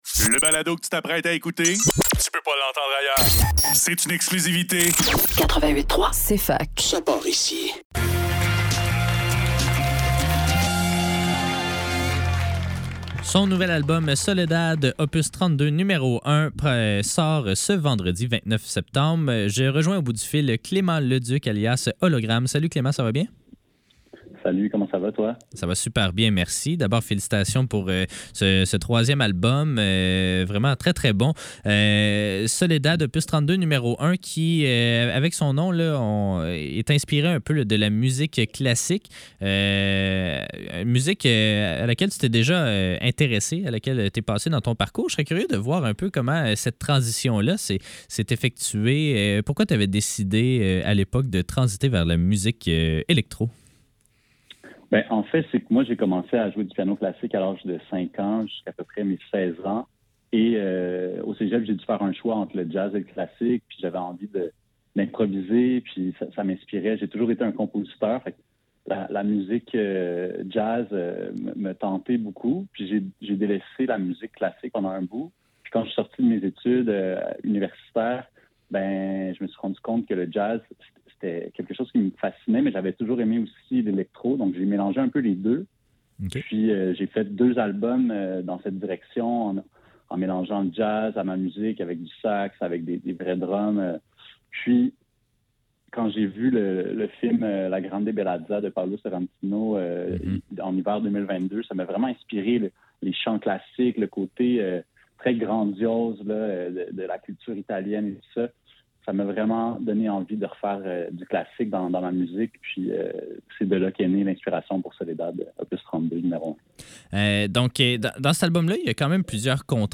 Faudrait que le tout l'monde en parle - Entrevue Hologramme